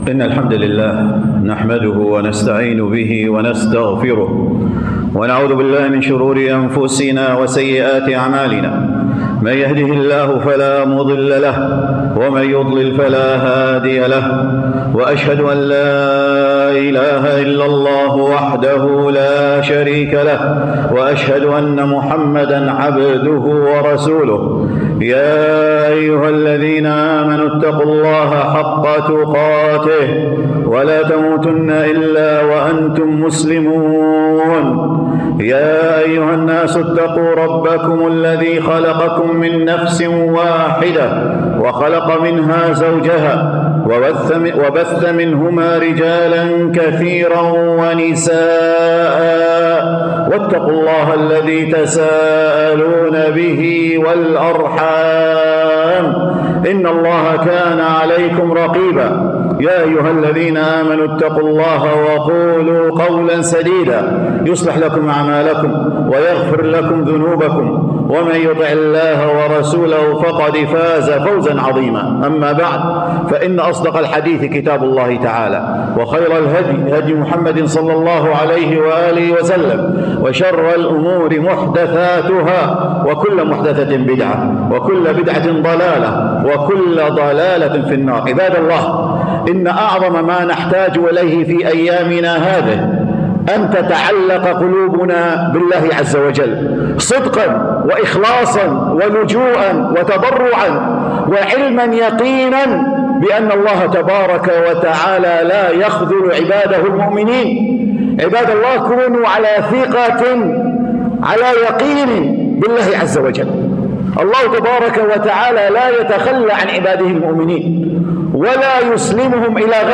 خطبة الجمعة بتاريخ 20 مارس